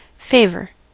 Ääntäminen
UK : IPA : /ˈfeɪvə(ɹ)/